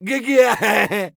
scav2_mutter_06.wav